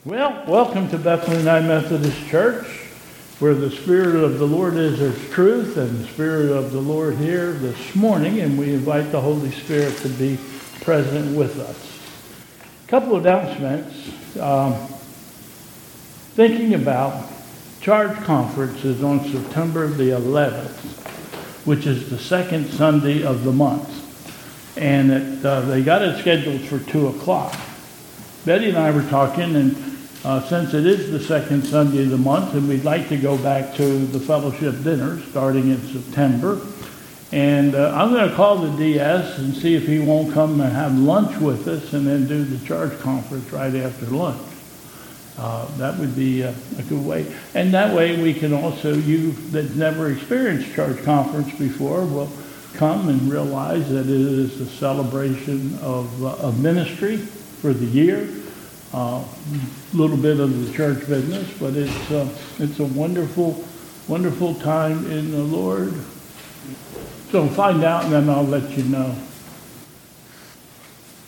2022 Bethel Covid Time Service
Welcome...and an announcement...